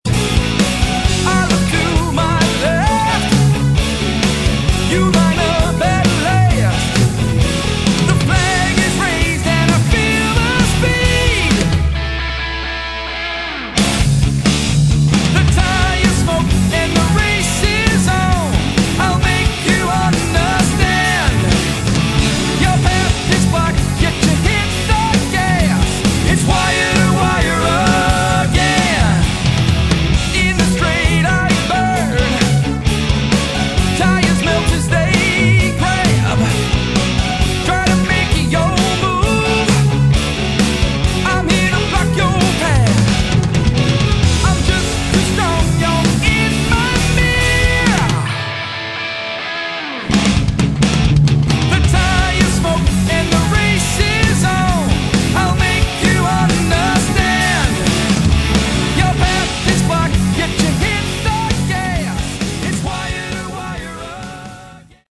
Category: AOR
lead vocals
guitars
bass guitar
drums & percussion
keyboards